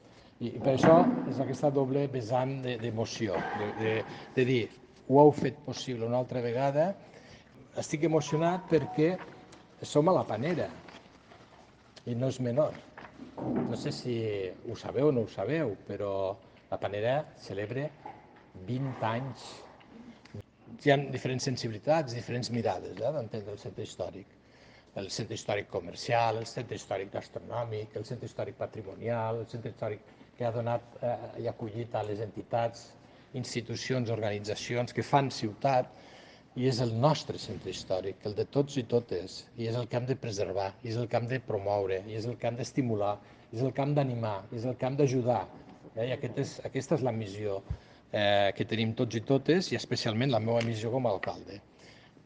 Corte de voz alcalde Larrosa El Abierto del Centro Histórico 2023 coincide con los actos de celebración de los 20 años del Centro de Arte La Panera que se encuentra ubicado en el barrio.